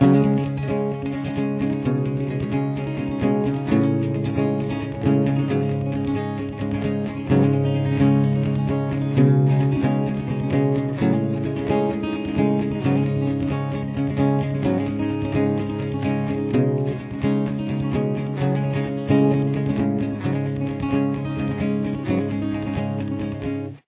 CAPO – Fourth Fret
Intro/Verse